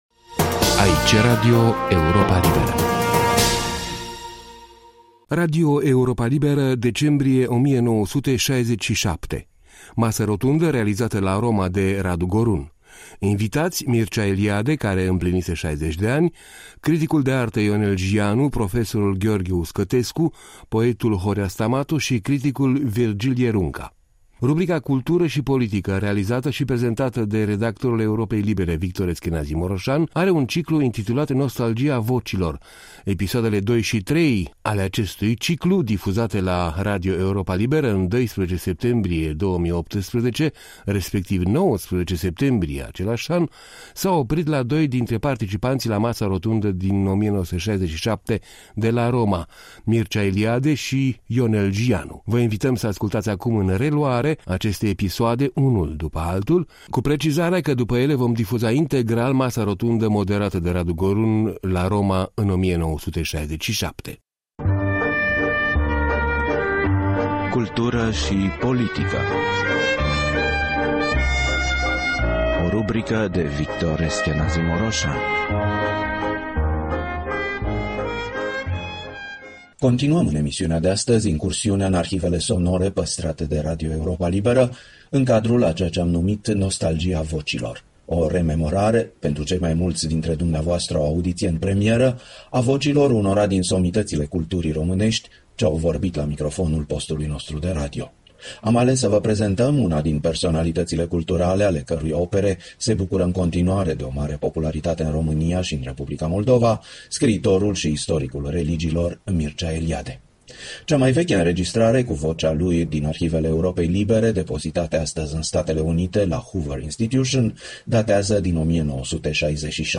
Radio Europa Liberă, Roma, decembrie 1967.